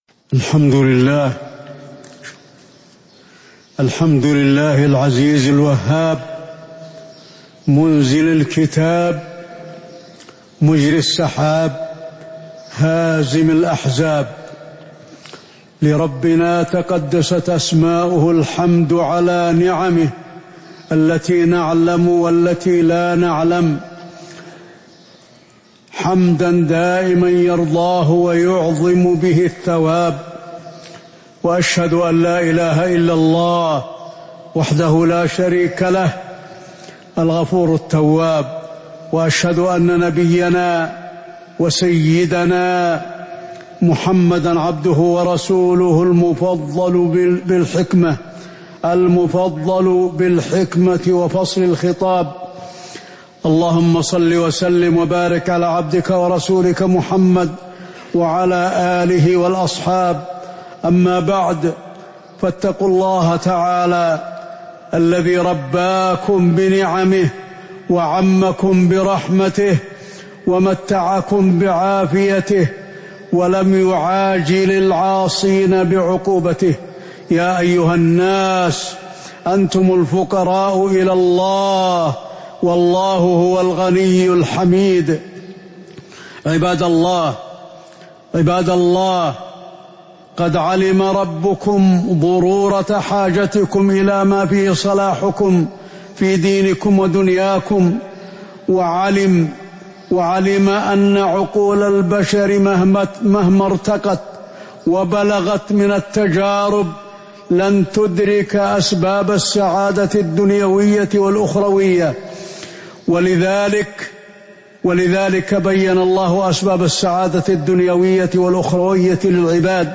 تاريخ النشر ١٣ ذو القعدة ١٤٤٤ هـ المكان: المسجد النبوي الشيخ: فضيلة الشيخ د. علي بن عبدالرحمن الحذيفي فضيلة الشيخ د. علي بن عبدالرحمن الحذيفي أسباب السعادة الدنيوية والأخروية